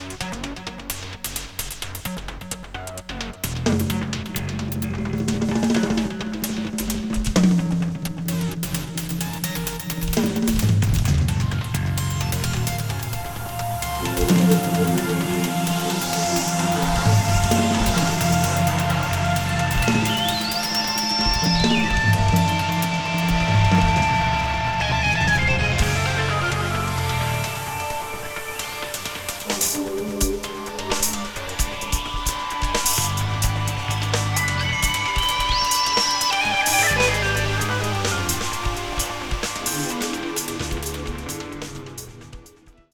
Chapman Stick
• Logic EVP88 (electric piano)
drums
guitar